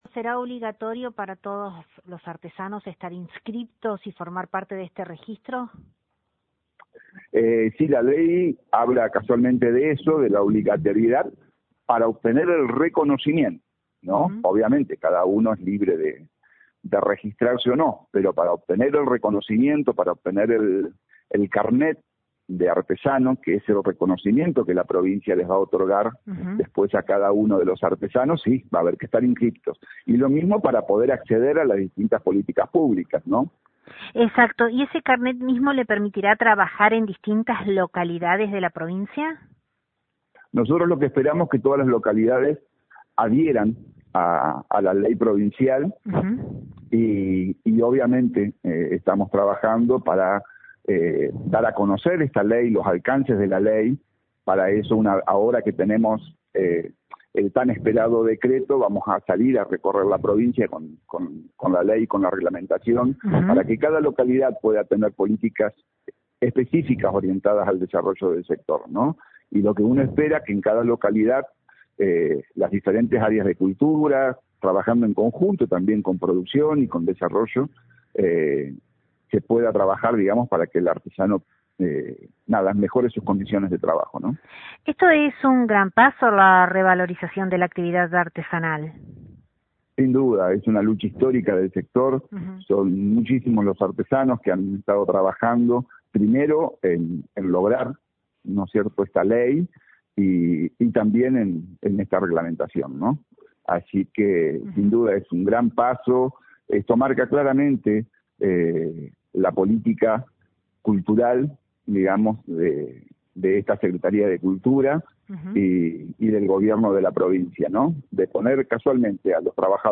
Desde LT39 NOTICIAS, dialogamos con el Director del mencionado organismo estatal, Rómulo Vidal; quien prima facie, hizo mención al decreto, que establece el primer paso para aplicar la ley provincial, estableciendo que se creará un Consejo Provincial de Artesanías de Entre Ríos (CoPrAER) que tendrá como propósito dictar una reglamentación interna en un plazo no mayor de 90 días desde la puesta en vigencia del Reglamento.